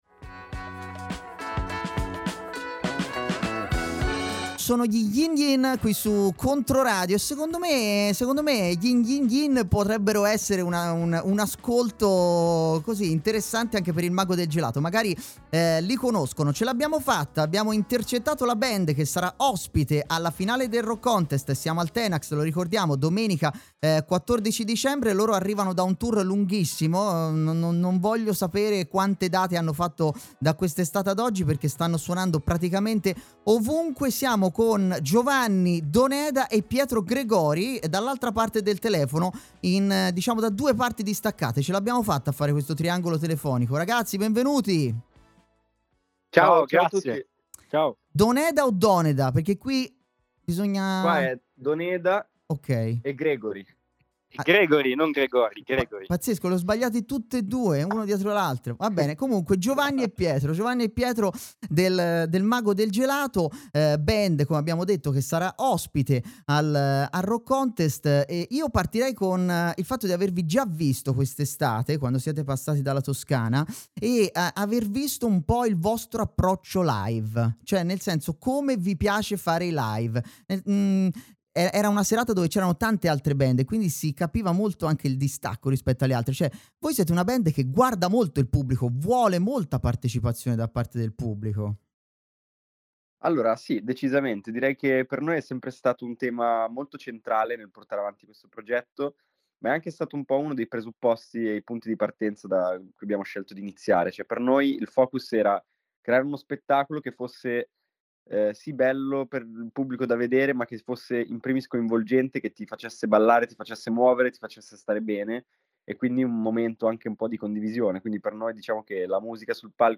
L'intervista!